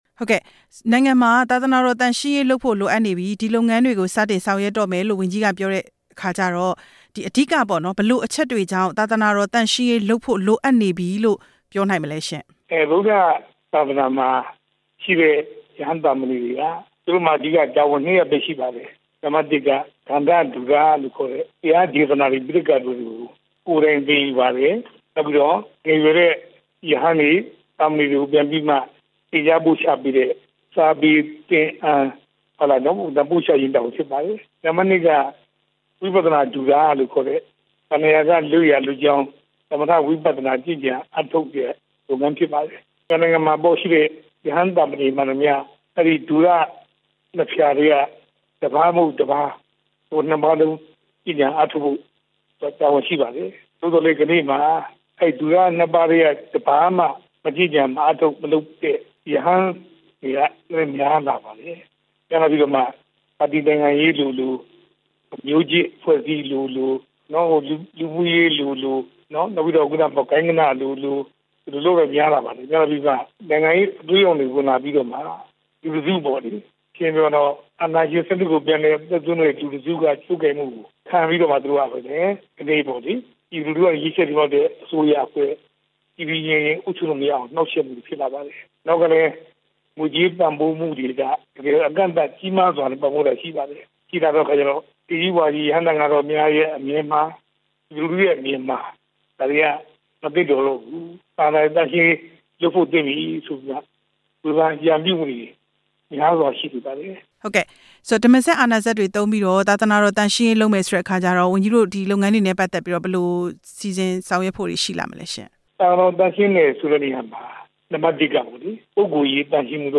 သာသာနာတော် သန့်ရှင်းရေး ဝန်ကြီး သူရဦးအောင်ကို နဲ့ မေးမြန်းချက်